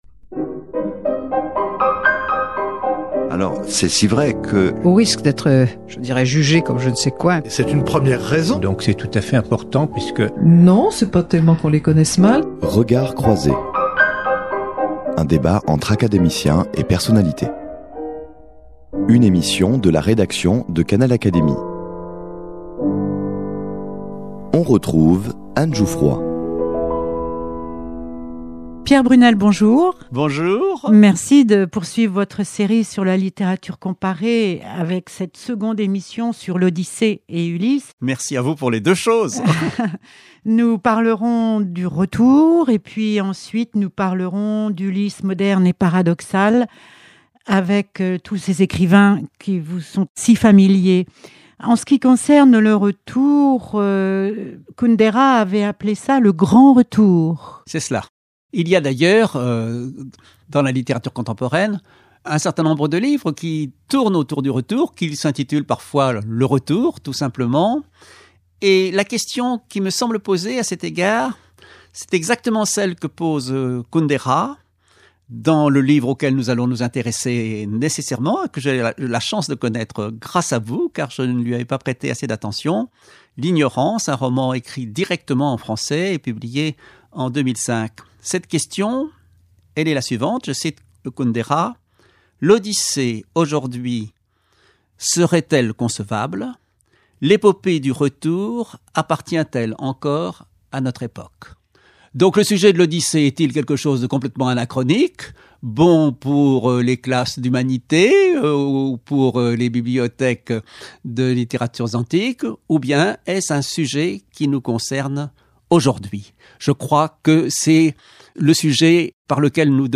Seconde émission consacrée à Ulysse et l’Odyssée : Pierre Brunel présente l’Ulysse moderne, et parfois paradoxal, d’Alain Peyrefitte, Milan Kundera, Ilarie Voronca et, évidement, James Joyce - suivis de Philippe Forest, Jean Paris et Frank Budgen, les accompagnateurs en littérature de Joyce. L’épopée du retour appartient-elle encore à notre époque ?